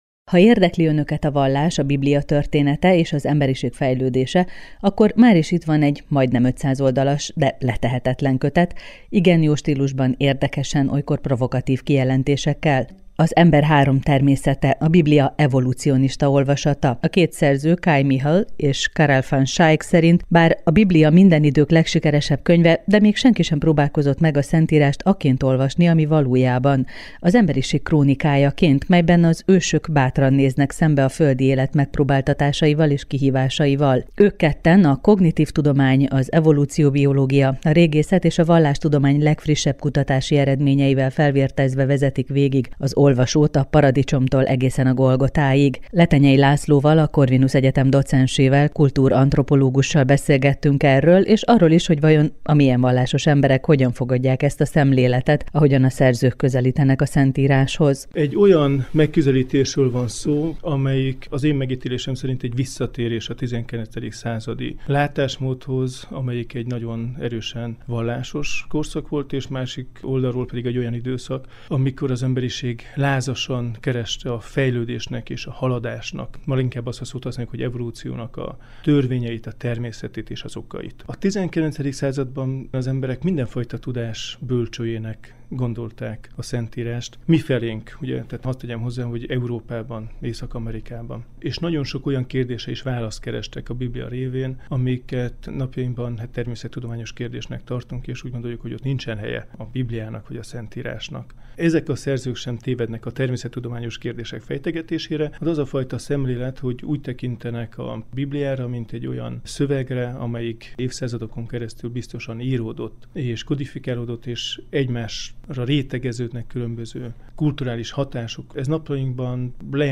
beszélget a Trend Fm Könyvben utazom című műsorában.